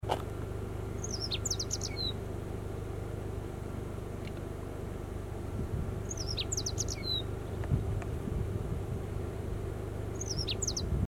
Hellmayr´s Pipit (Anthus hellmayri)
Life Stage: Adult
Location or protected area: Villa de Merlo
Condition: Wild
Certainty: Photographed, Recorded vocal